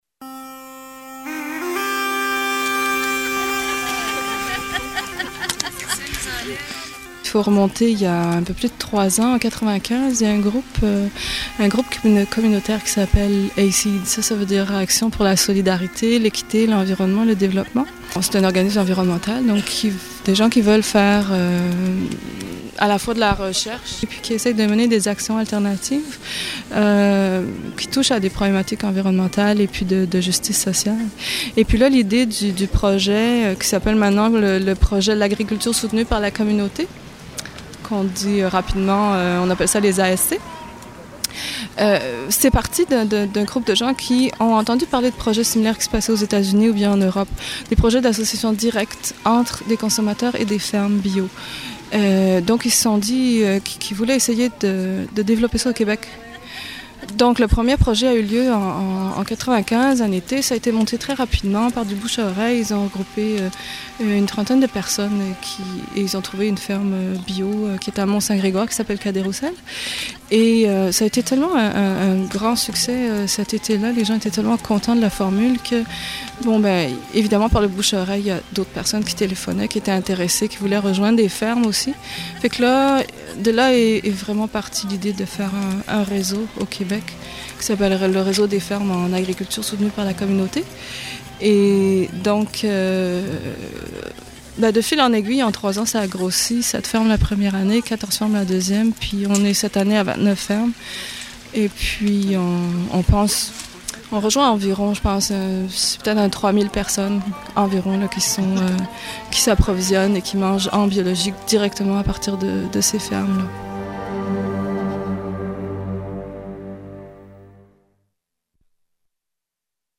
Reportage 10.18'